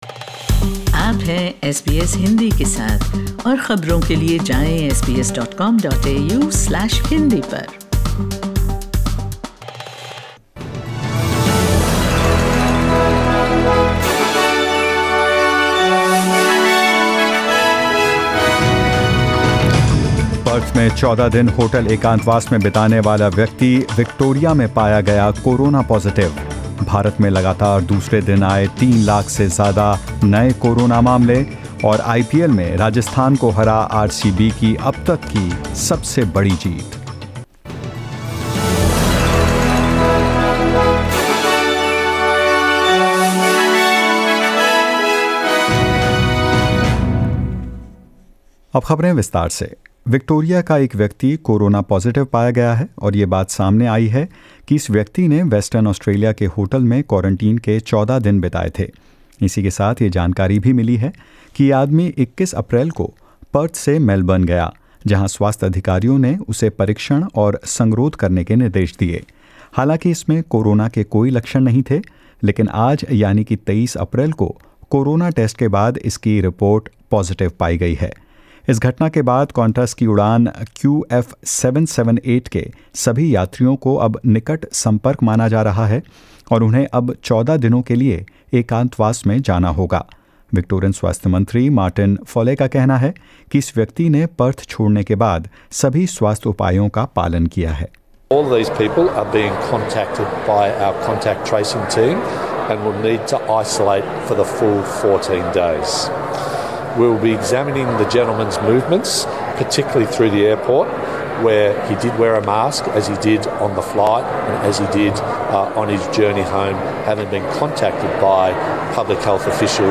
News in Hindi: India records world's highest single-day spike in coronavirus cases